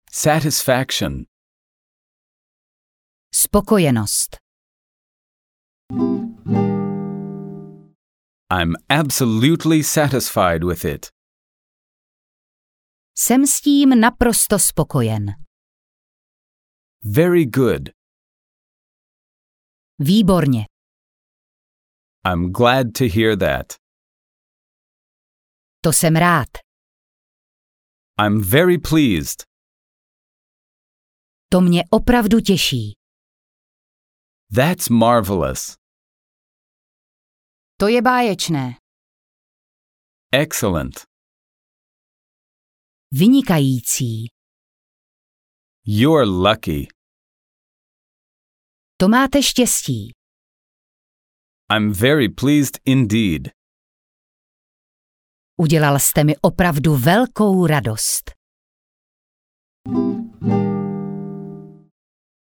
Kurz anglické konverzace - anglicko-česká část audiokniha
Ukázka z knihy